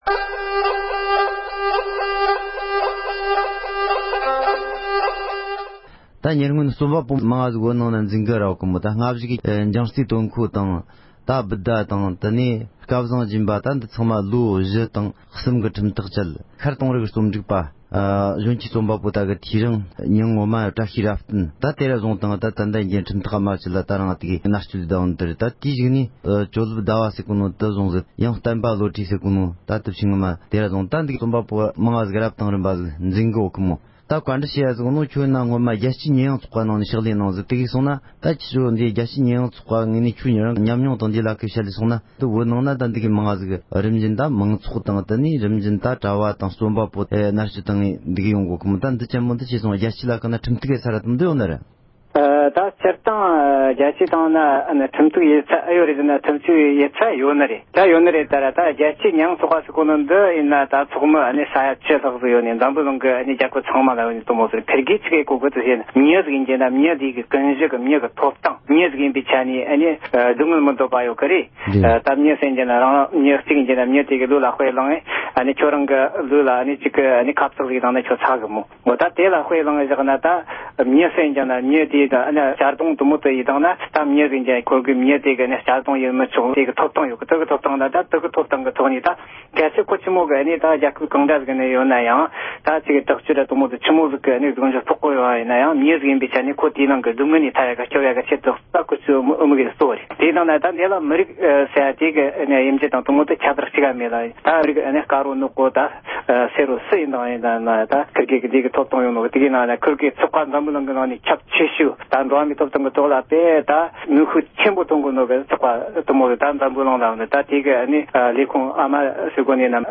བོད་ཀྱི་རྩོམ་པ་པོ་སོགས་འཛིན་བཟུང་བྱས་པ་དེ་དག་རྒྱལ་སྤྱིའི་ཁྲིམས་ཁང་དུ་ཁྲིམས་གཏུག་བྱེད་ཕྱོགས་ཀྱི་ཐད་གླེང་མོལ།